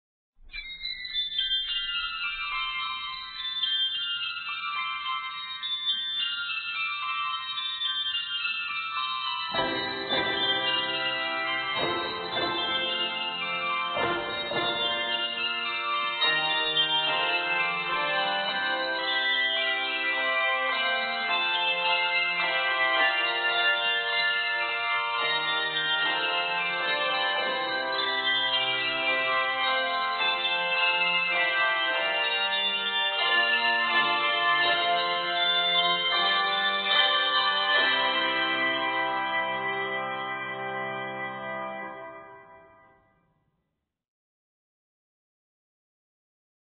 these exciting bell flourishes
will immediately create an atmosphere of celebration.